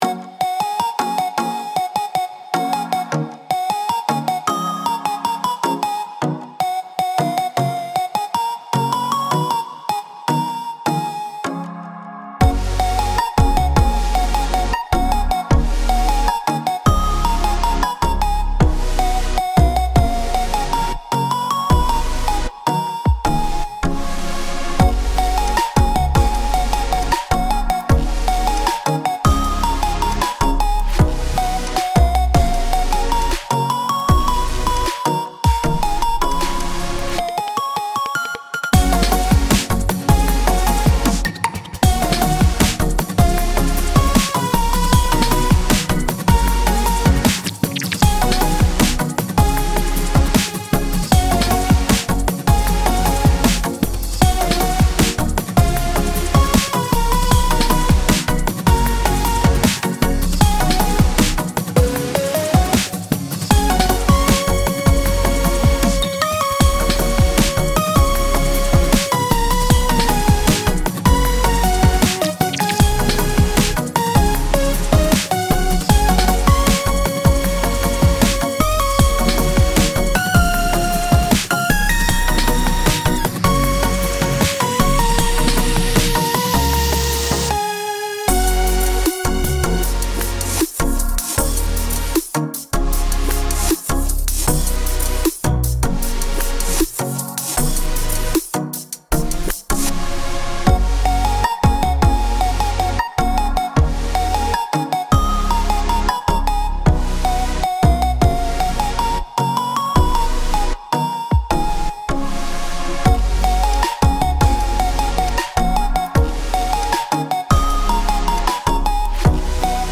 優しく可愛い、そしてちょっぴりエモい。雰囲気のBGMです。